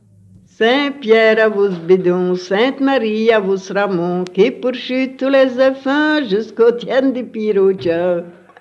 Genre : chant
Type : chanson narrative ou de divertissement
Interprète(s) : Anonyme (femme)
Lieu d'enregistrement : Surice
Support : bande magnétique